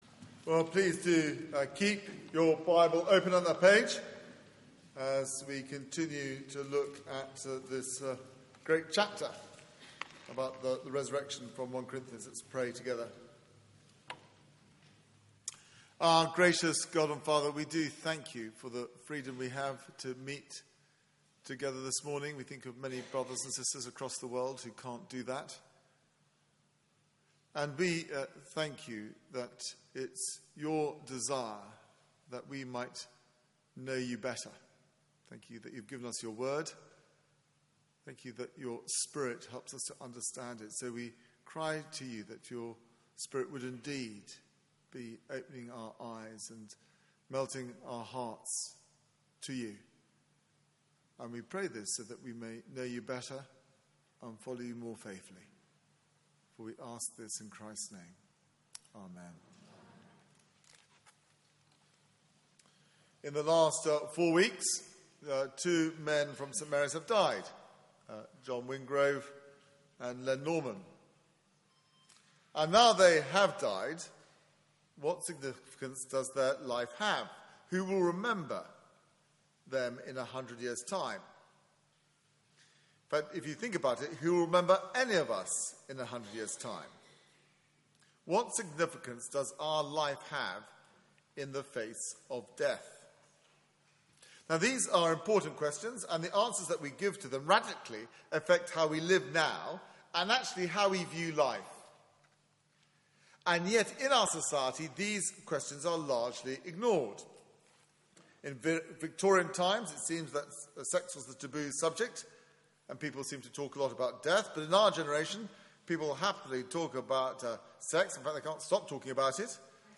Media for 9:15am Service on Sun 03rd Apr 2016